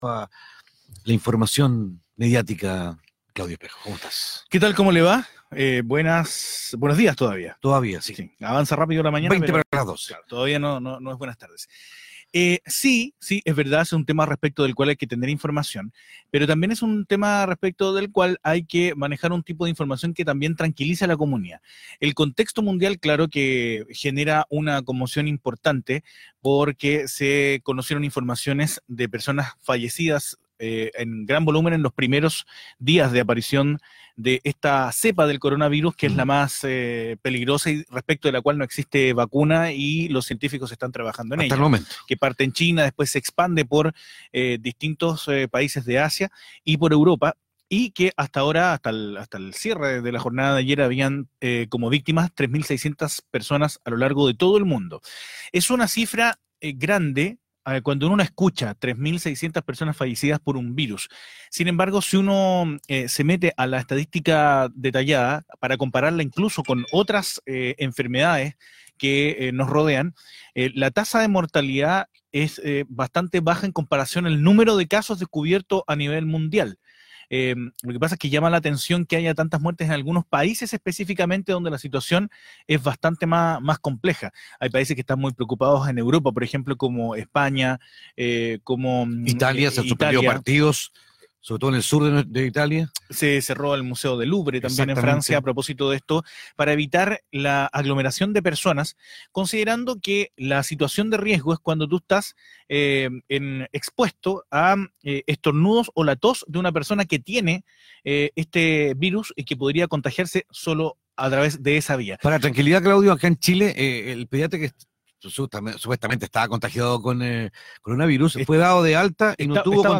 A continuación, el audio de la conversación: